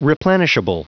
Prononciation du mot replenishable en anglais (fichier audio)
Prononciation du mot : replenishable